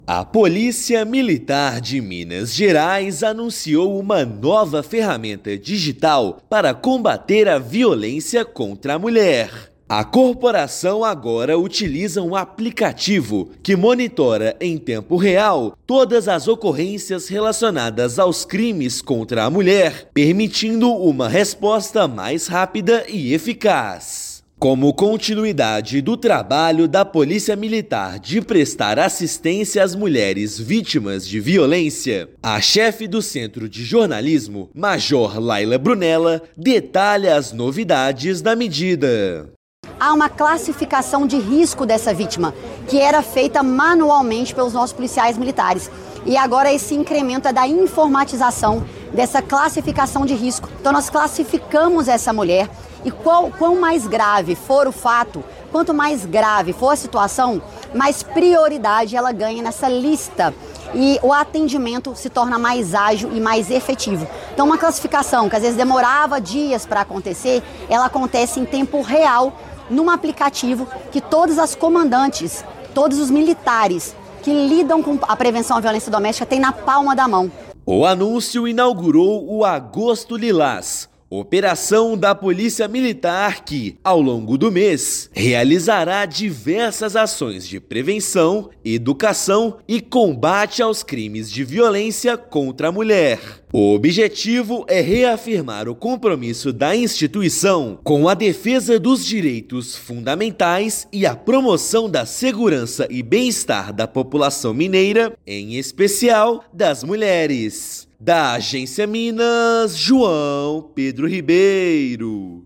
Ferramenta permite que comandantes e todos os militares envolvidos no atendimento de proteção à mulher recebam, em tempo real, a atualização do sistema, à medida que as ocorrências são registradas. Ouça matéria de rádio.